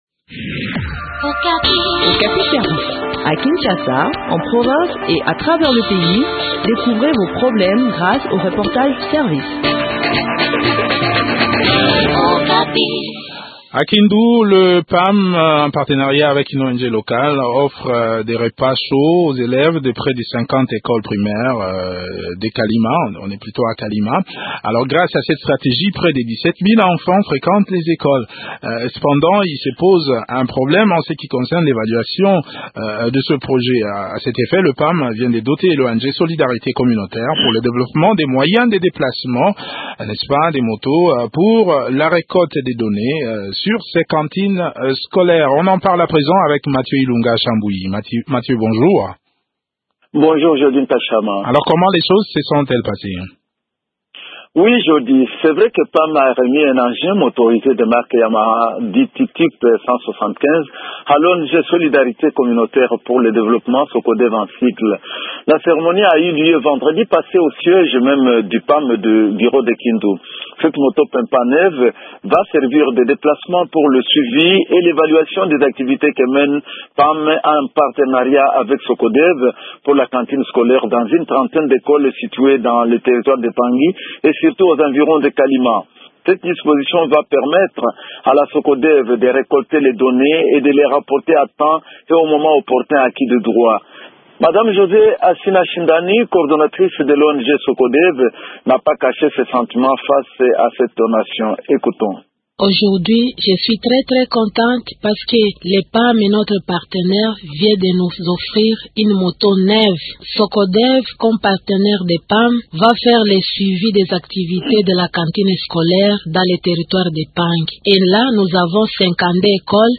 Le point sur l’exécution de ce projet de cantine scolaire dans cet entretien